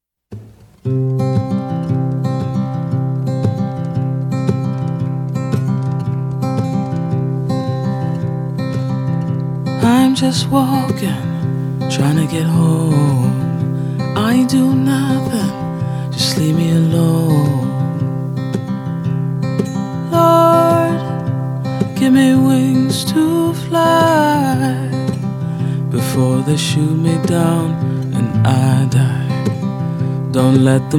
Basse (instrument)